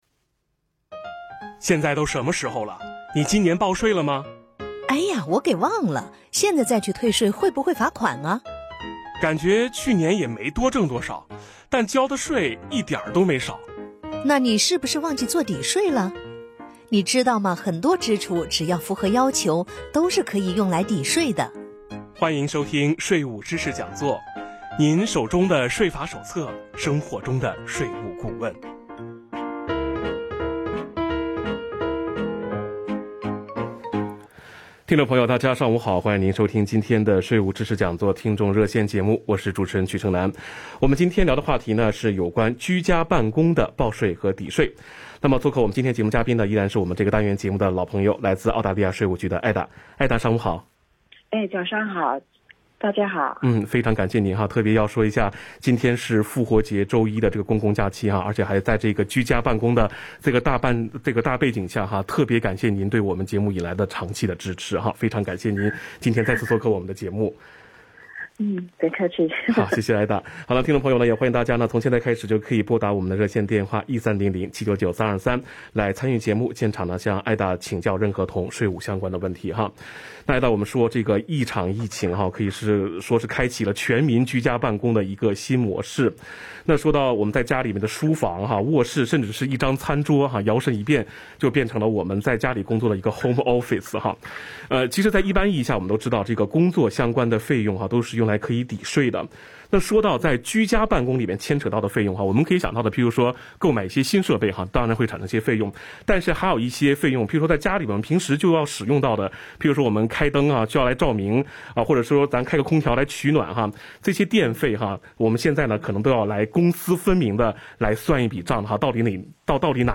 tax_talkback_april_13.mp3